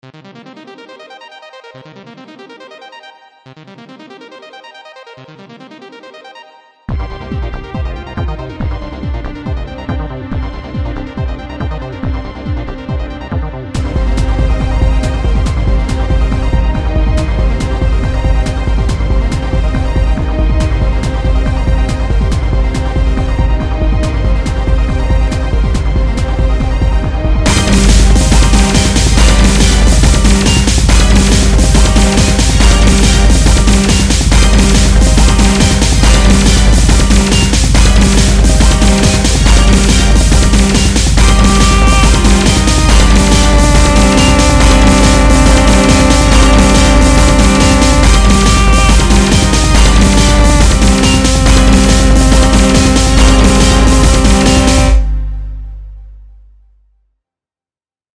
song that will hurt your ears - Electronic - Young Composers Music Forum